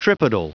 Prononciation du mot tripodal en anglais (fichier audio)
Prononciation du mot : tripodal